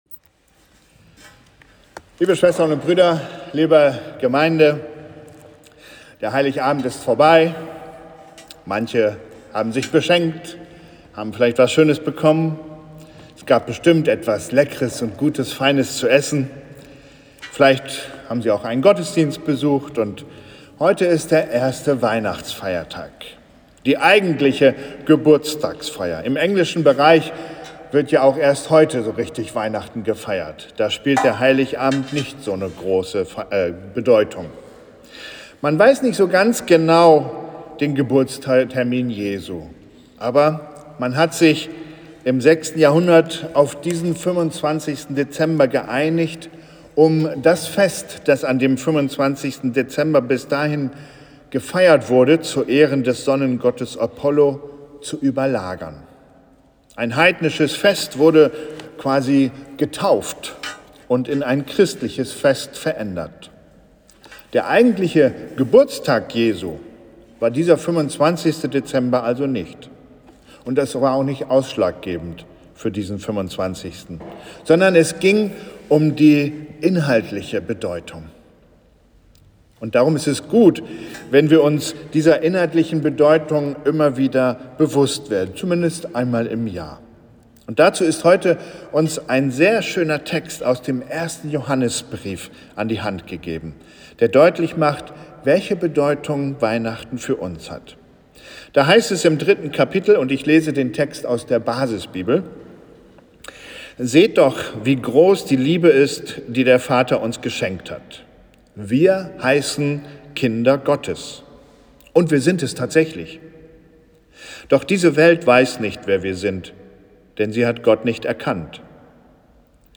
Gottesdienst am 1. Weihnachtsfeiertag